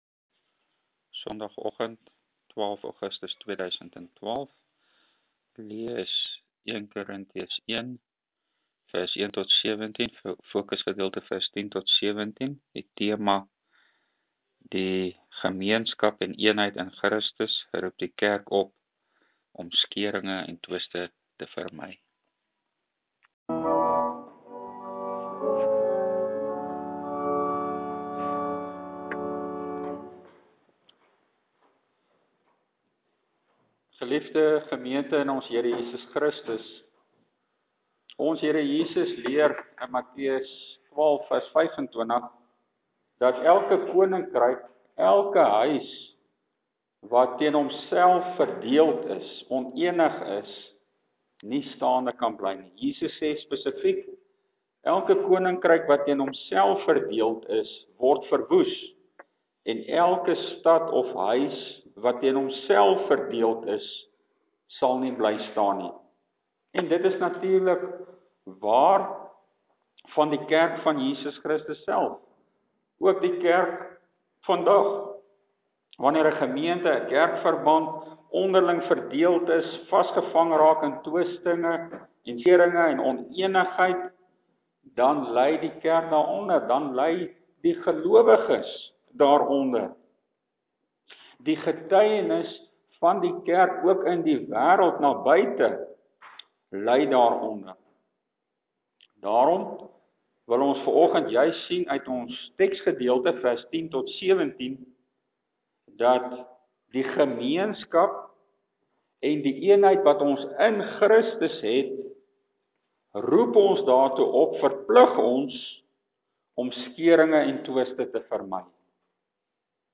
Preke uit 1 Korinthiërs: 1 Kor.1:10-17 Ware eenheid in Christus teenoor skeuringe